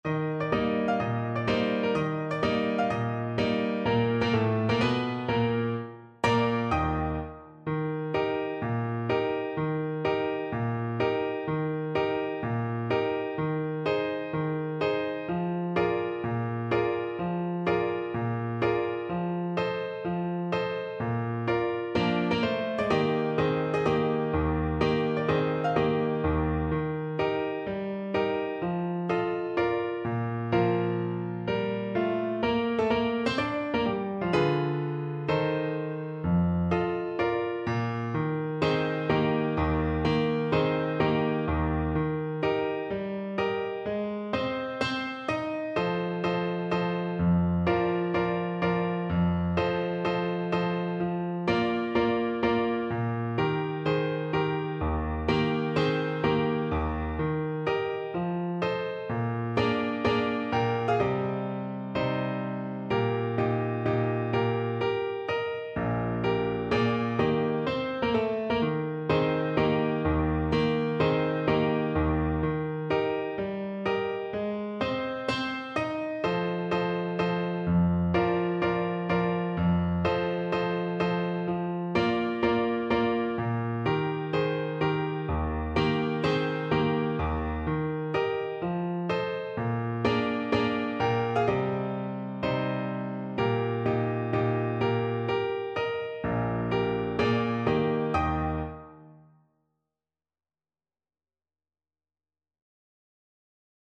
Moderato =126
4/4 (View more 4/4 Music)